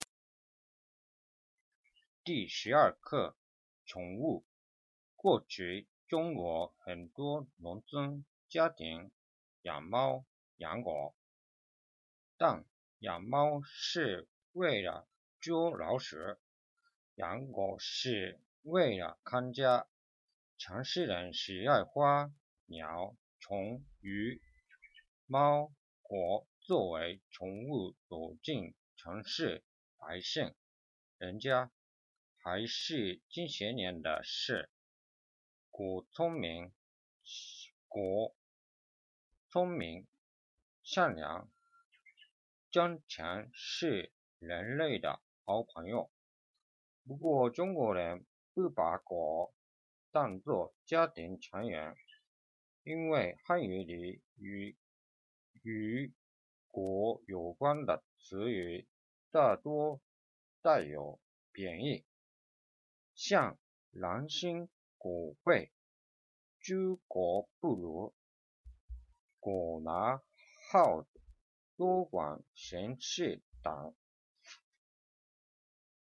念课本儿—中级汉语教材【第12课宠物】